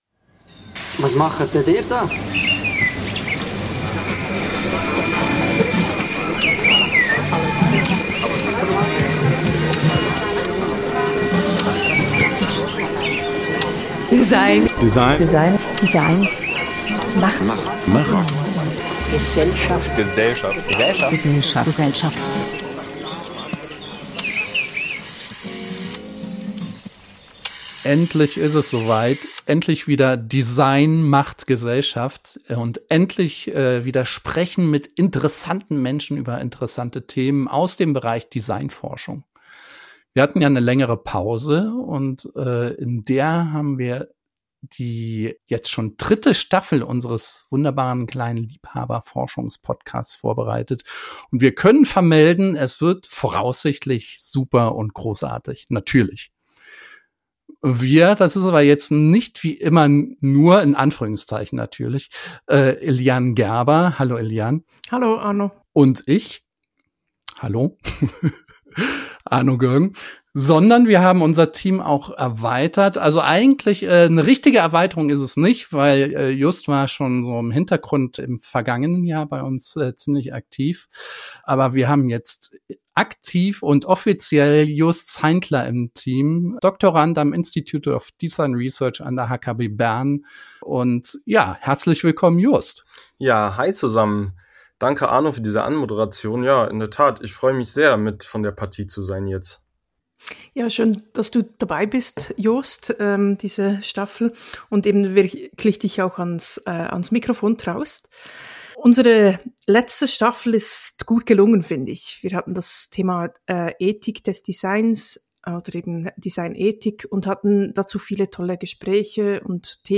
Wie bereits beim letzten Mal blickt das Moderationsteam in dieser ersten Folge auf die letzte Staffel zurück, reflektiert und ordnet ein. Ebenso wird ein Ausblick auf das übergeordnete Thema der dritten Staffel und die ersten Folgen präsentiert.